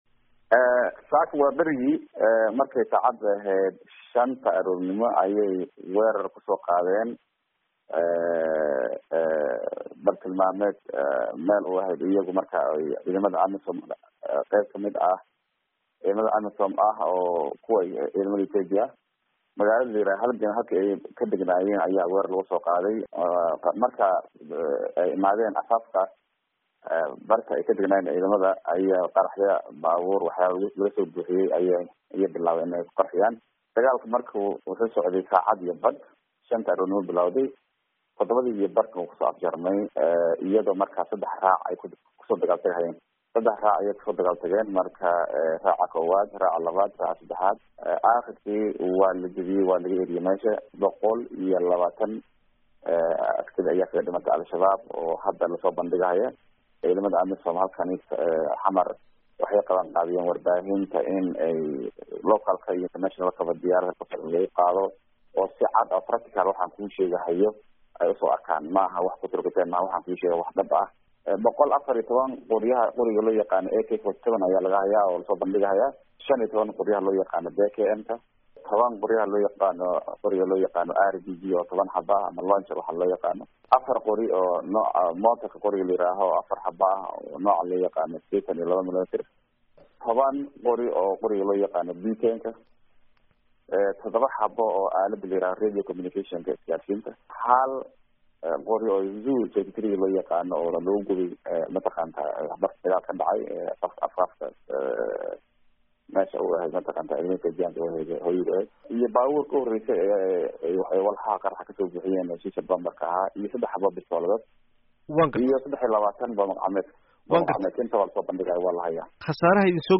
Wareysi: Jamaaluddiin Mustafa